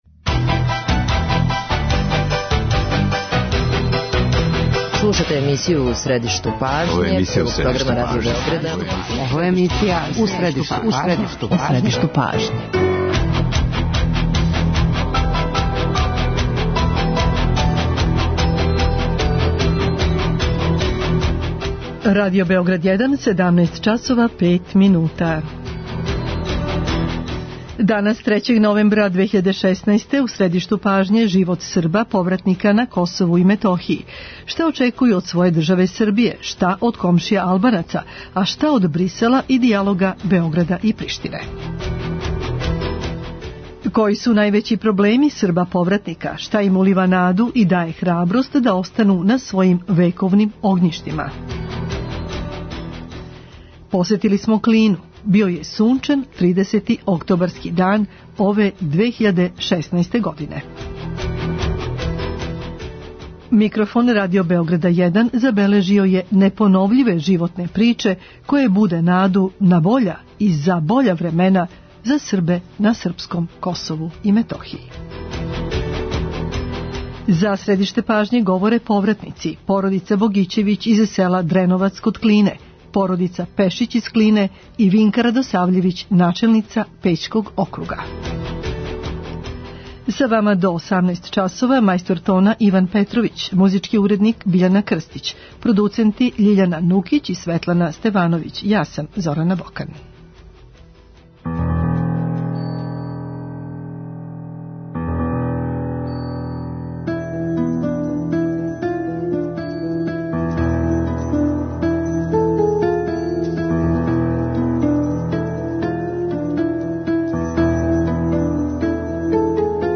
Посетили смо Клину.